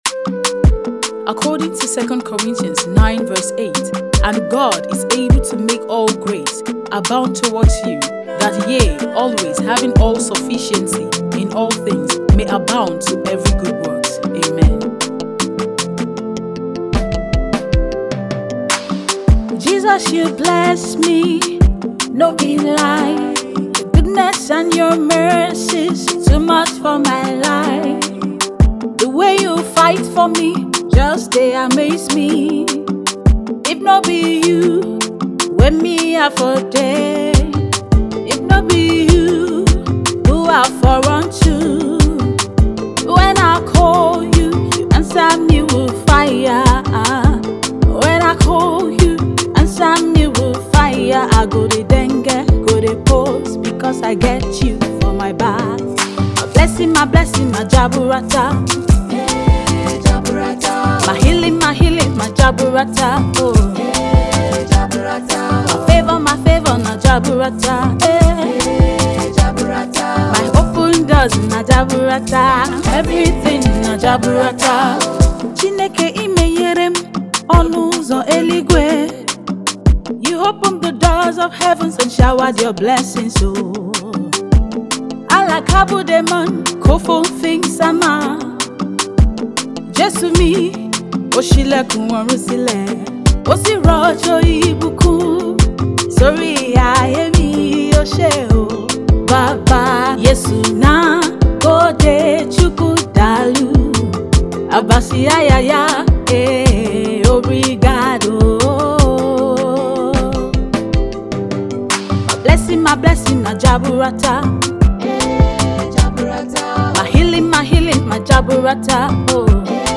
a vibrant gospel track